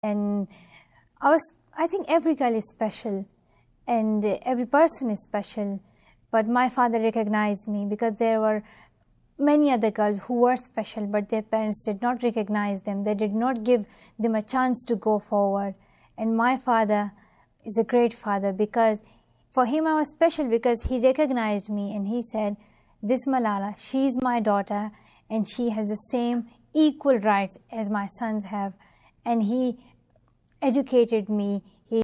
On the one-year anniversary of the attack, Malala sat down for her first Canadian interview with CBC Radio’s Anna Maria Tremonti to talk about her continuing fight for female education in Pakistan, and her life in the crosshairs of the Taliban.
—Audio: CBC Radio’s The Current with Anna Maria Tremonti. “Interview with Malala Yousafzai.” 2013.